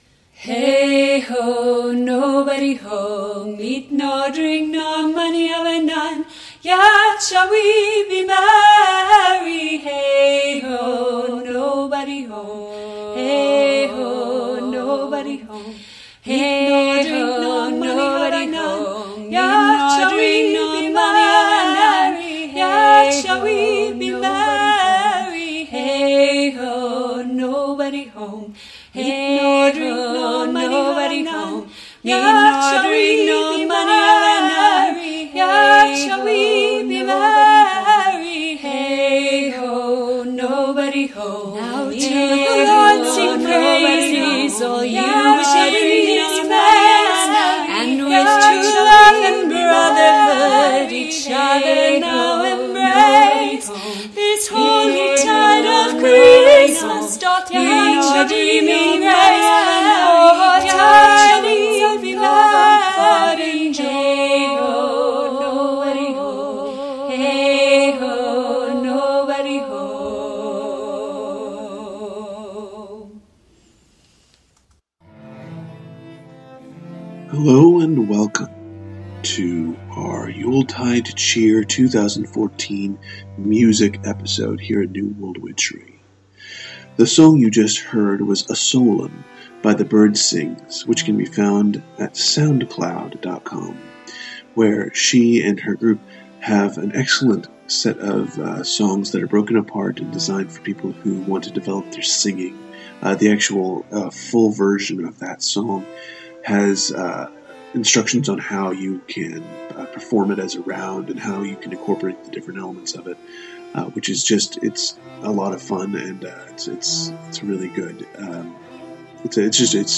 Episode 72 – Yuletide Cheer! 2014 (Part Three) Our last holiday show of 2014 is our music episode, featuring songs that should put you in the yuletide spirit.